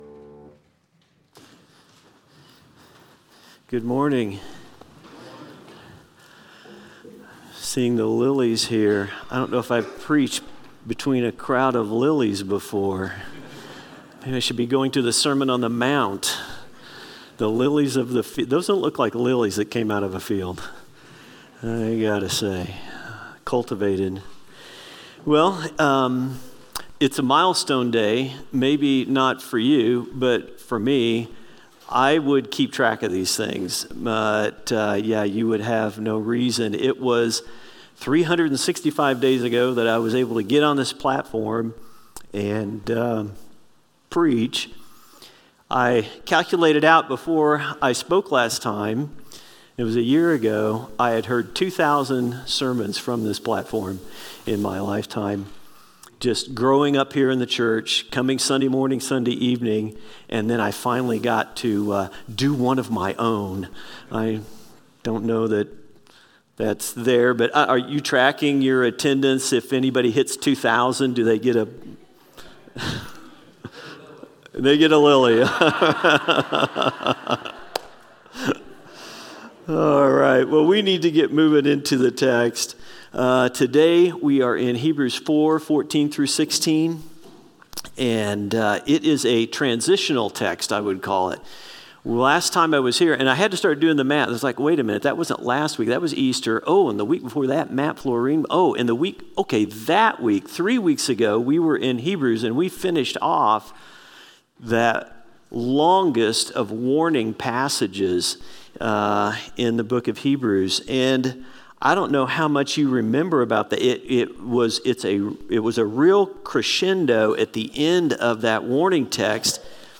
Sermons – Wichita Bible Church